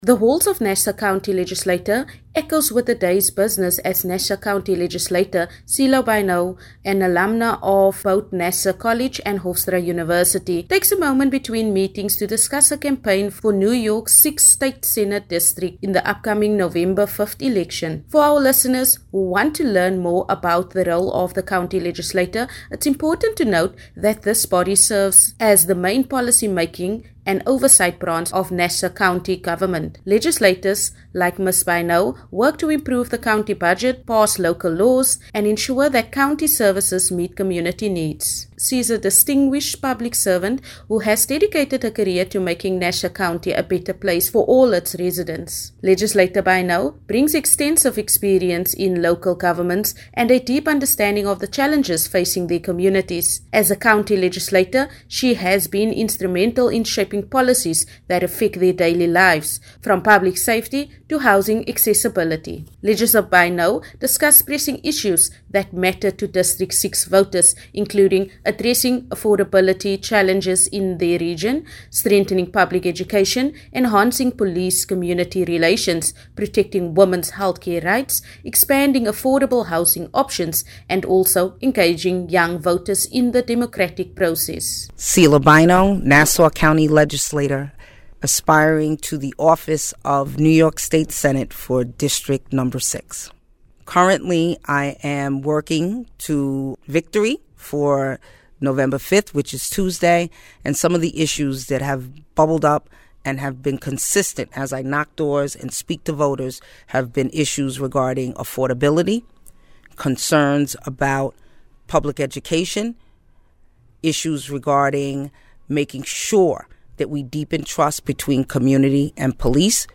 Sudan War Entering Its 3rd Year - How Do African Leaders Move Towards Peace? (Press Briefing)